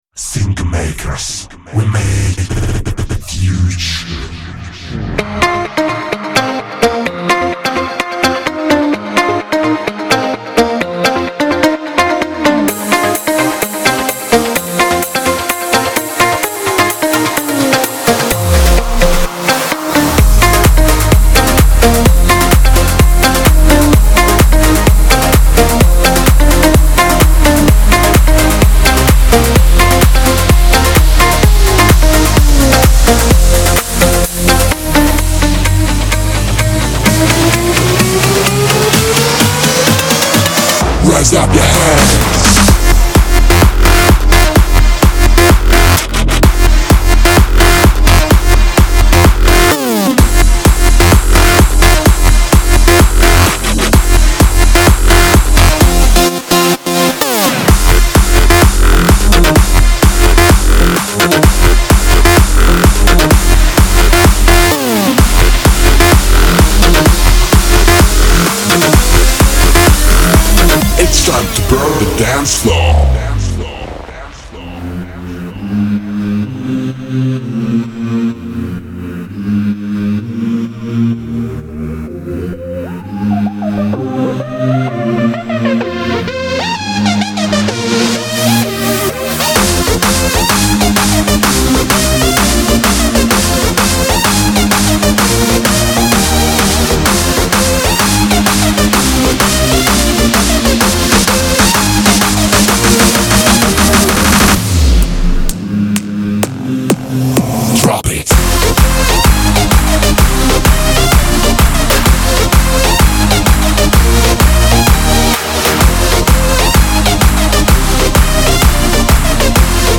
带有大量疯狂的EDM降落合成器，丰富的低音线，强劲的鼓声，单发，主题曲，人声，效果器，音色等等。
• 50 Drop Bass Loops
• 60 Drop Synth Loops
• 70 Drum Loops
• 25 Vocal Shouts